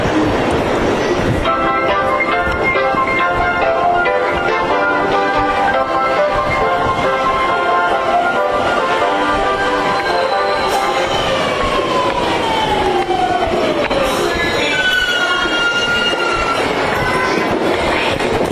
完全版 後ろに到着した列車は…　209系1000番台！